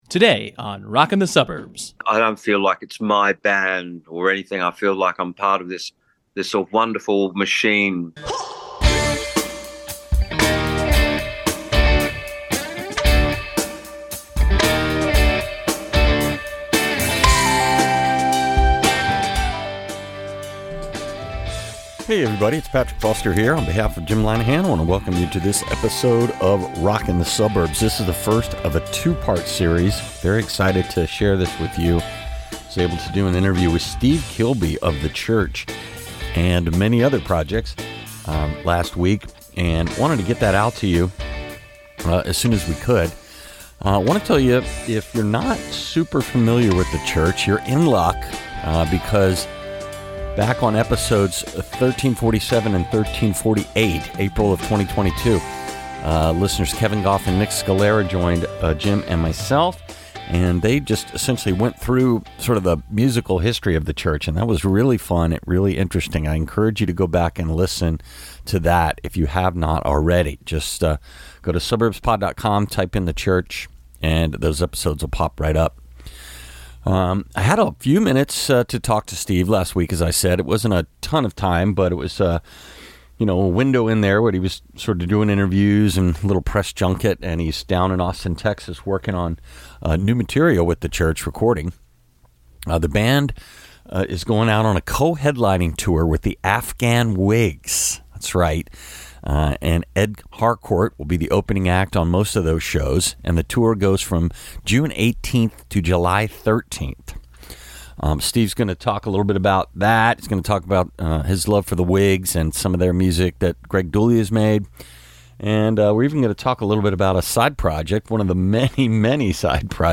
Interview: Steve Kilbey of the Church, Part 1